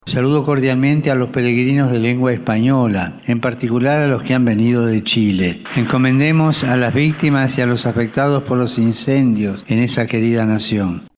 Entre las personalidades internacionales que se refirieron públicamente al hecho, el domingo 4 de febrero, en la tradicional misa celebrada en El Vaticano, el Papa Francisco pidió a la comunidad rezar por las víctimas y heridos de los incendios forestales.